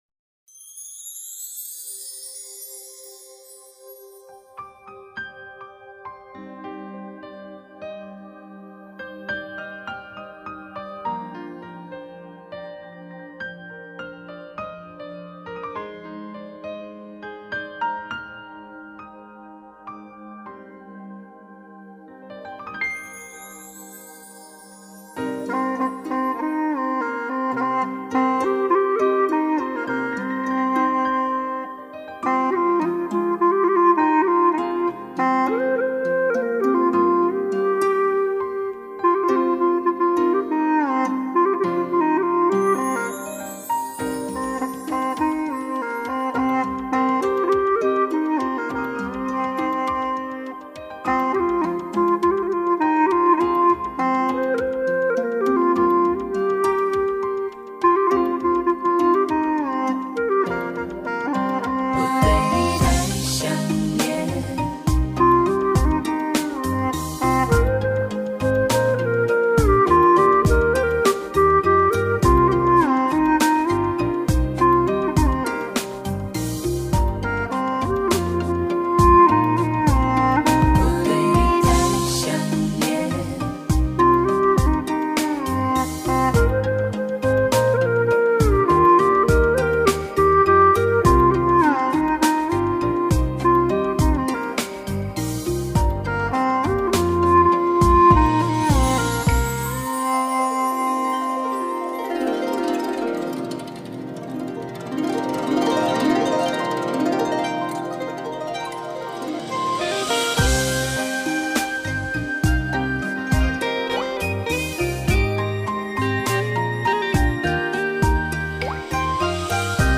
调式 : 降E 曲类 : 流行
叠音、滑音、顿音，一气呵成。